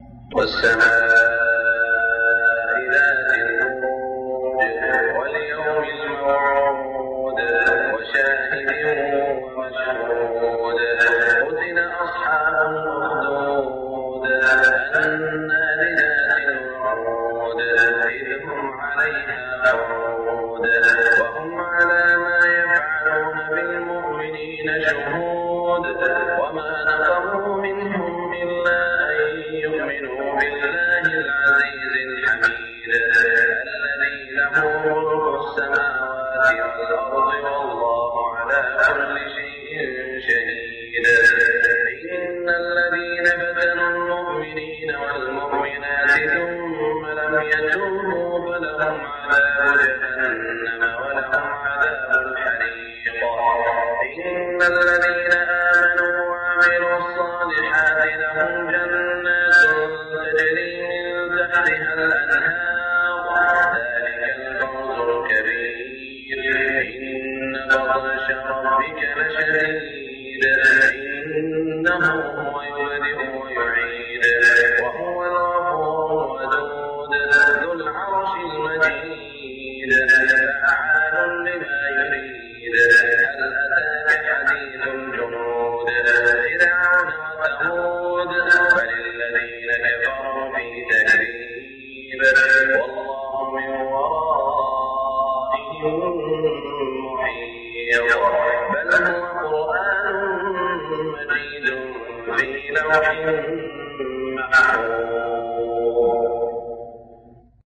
فجرية نادرة من سورة البروج عام ١٤٢٤ > 1424 🕋 > الفروض - تلاوات الحرمين